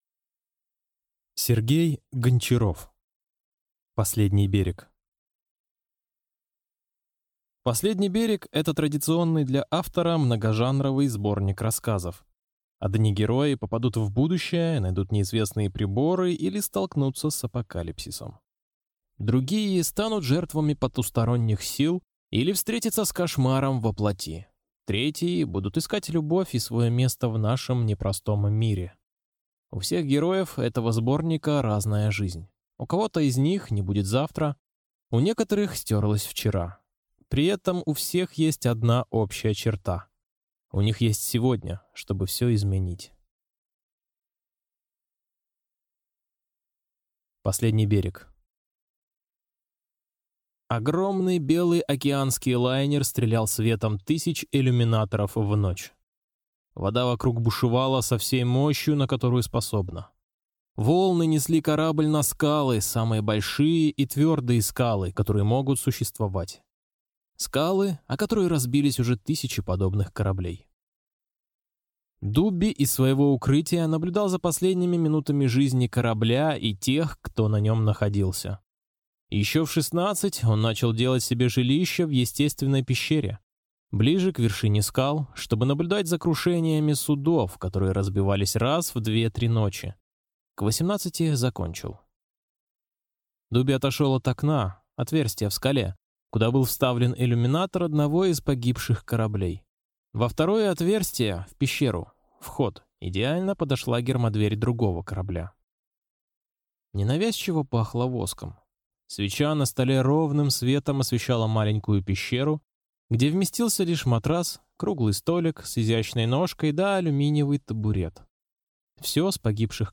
Аудиокнига Последний берег | Библиотека аудиокниг